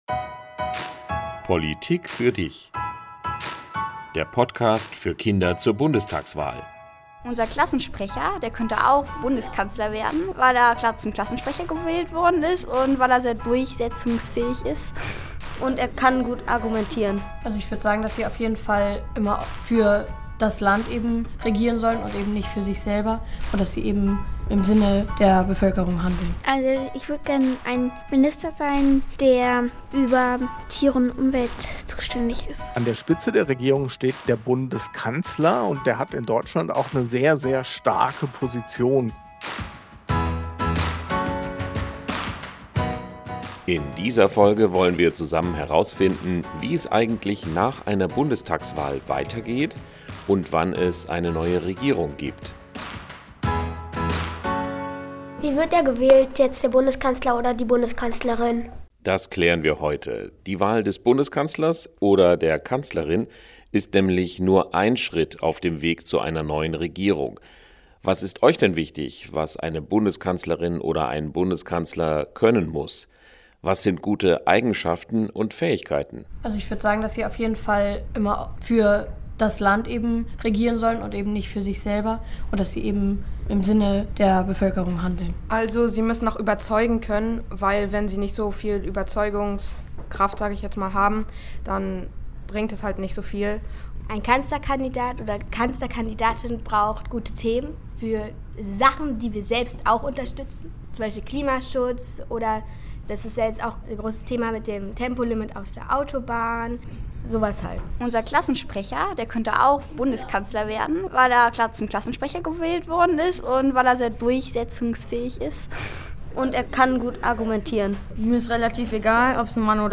Podcast für Kinder zur Bundestagswahl Podcast herunterladen Was passiert eigentlich nach der Wahl mit der alten Regierung?